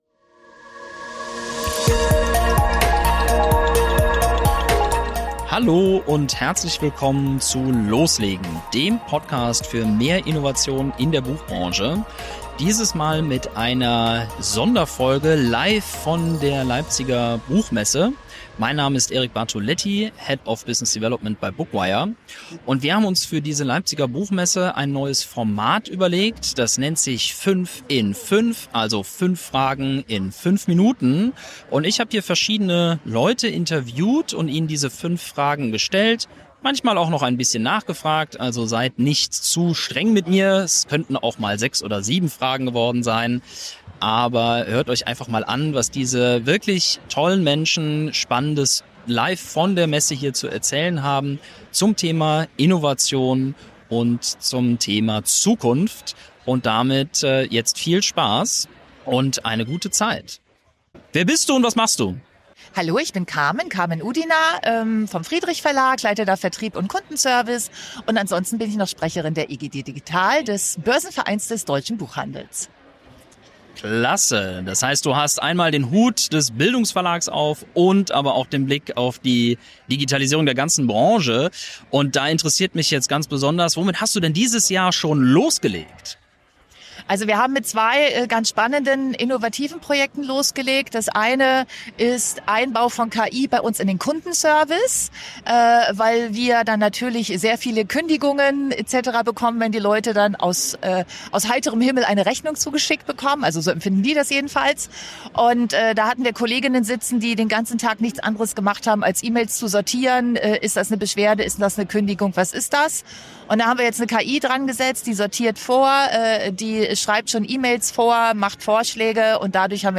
S3E2 – Sonderfolge von der Leipziger Buchmesse 2026 ~ Loslegen - der Podcast für mehr Innovation in der Buchbranche Podcast
Insbesondere auf der Leipziger Buchmesse, für die wir uns ein neues Format überlegt haben: 5 in 5, also 5 Fragen in 5 Minuten. Wobei es schlussendlich so viel Relevantes zu besprechen gab, dass die meisten Interviews doch etwas länger geworden sind. Daher gibt es in dieser Sonderfolge fast 90 Minuten spannende Perspektiven, Ansichten und Erfahrungen zu Innovation und der Zukunft unserer Branche.